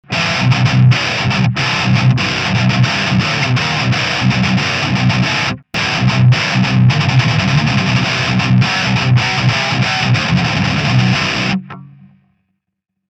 Плосковатый детальный звук это очень и очень хорошо - имхо Вложения podxt___test___metalizer.mp3 podxt___test___metalizer.mp3 308 KB · Просмотры: 199